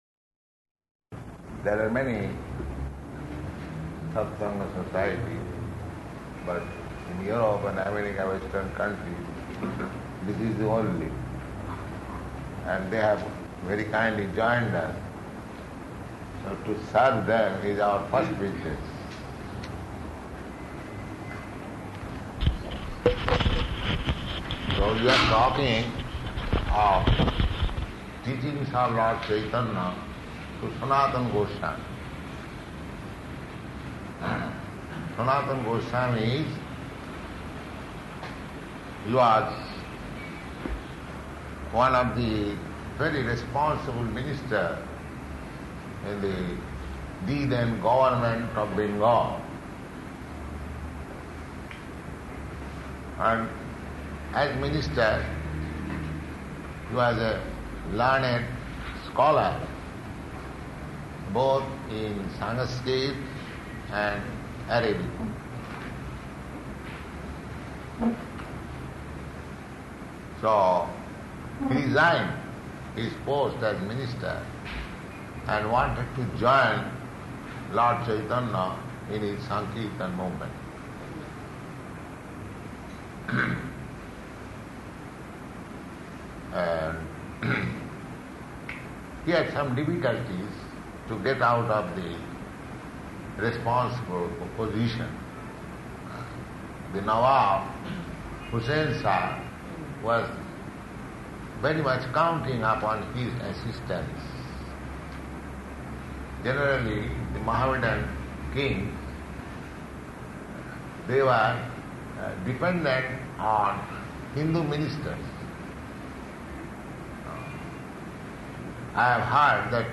Type: Lectures and Addresses
Location: Bombay